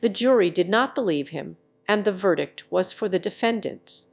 Matcha-TTS - [ICASSP 2024] 🍵 Matcha-TTS: A fast TTS architecture with conditional flow matching